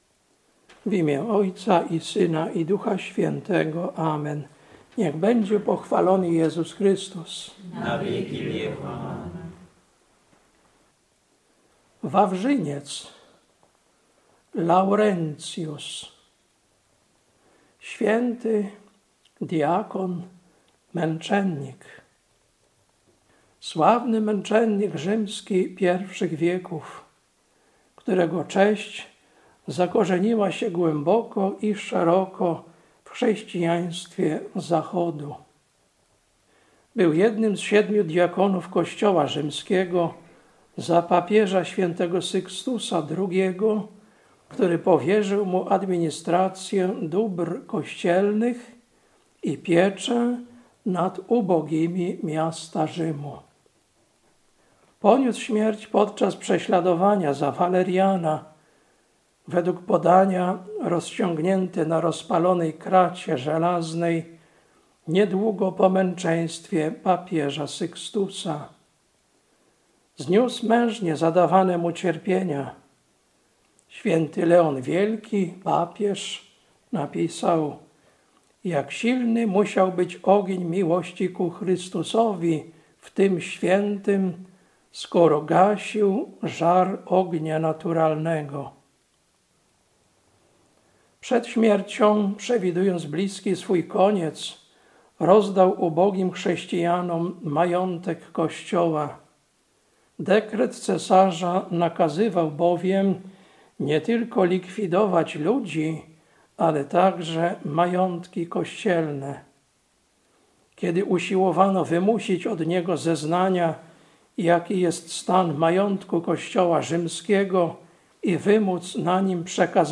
Kazanie na na święto św. Wawrzyńca, Męczennika, 10.08.2025